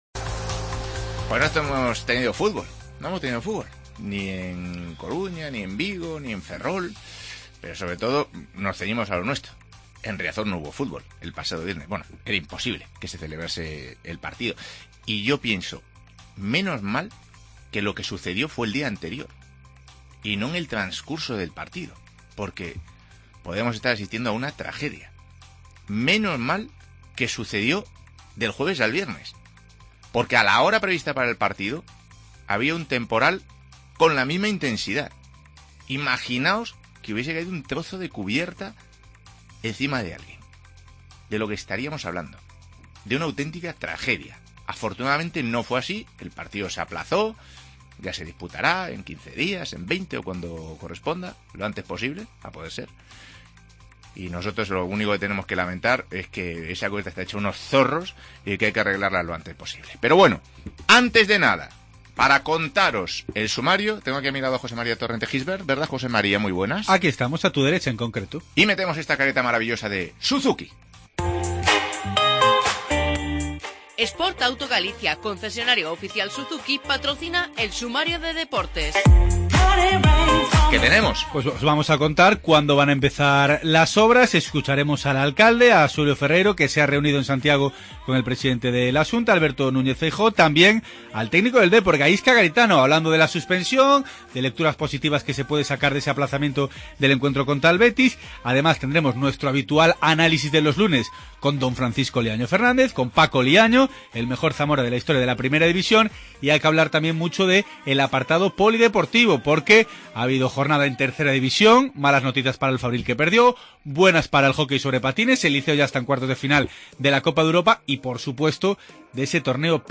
AUDIO: Obras en Riazor, escuchamos al alcalde Xulio Ferreiro y a Garitano.